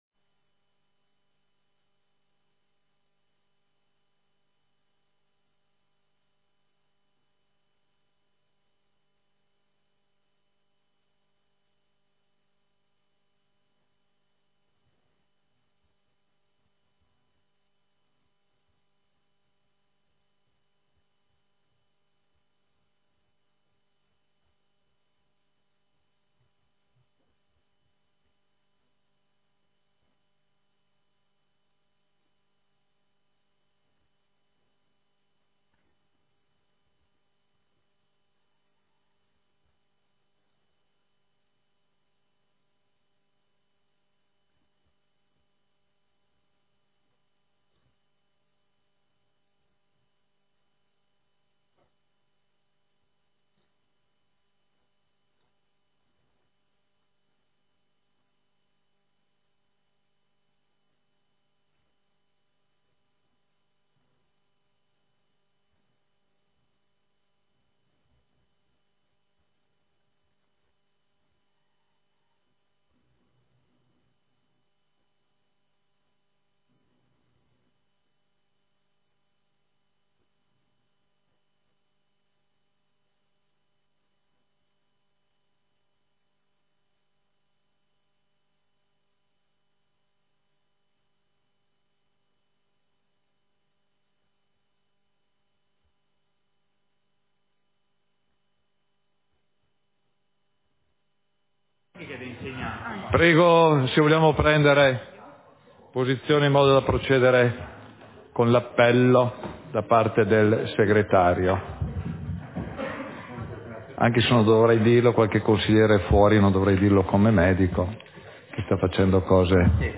Seduta del consiglio comunale - 13 novembre 2024